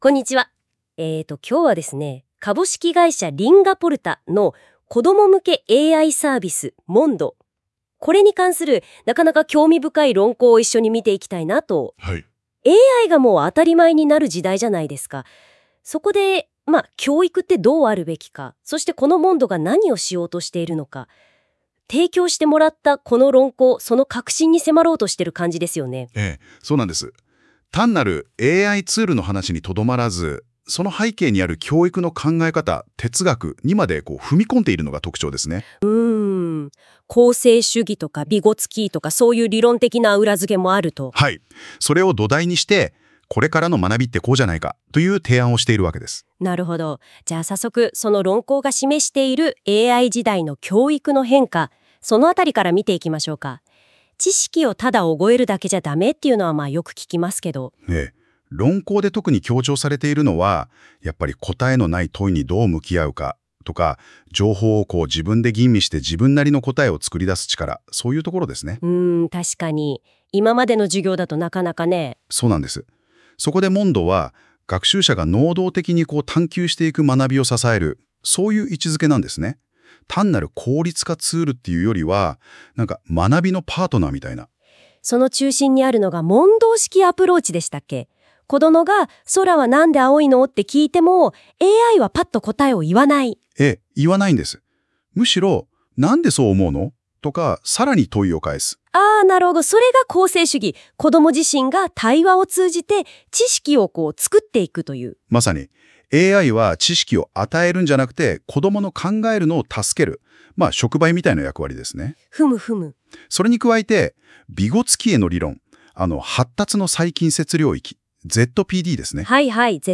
＊このページの音声はGoogleのNotebookLMで作成しました。
▶AI音声で概要をお聞きいただけます。